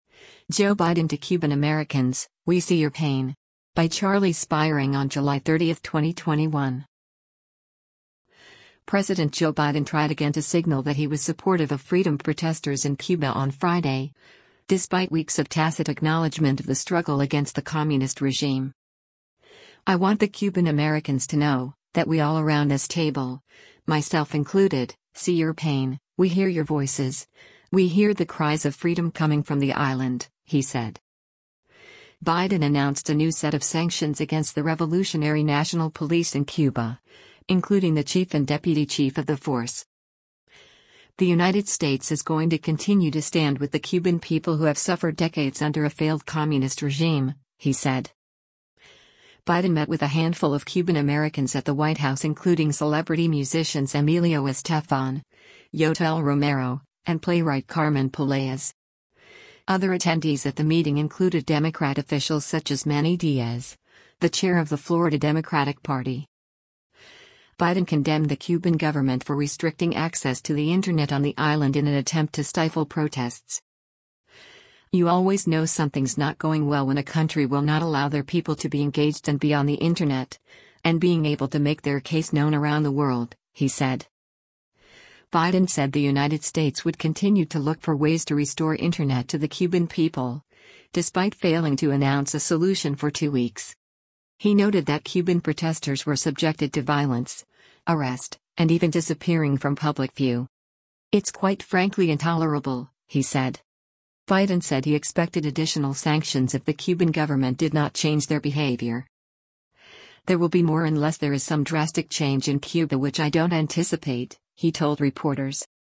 US President Joe Biden speaks during a meeting with Cuban-American leaders in the State Dining Room of the White House in Washington, DC on July 30, 2021.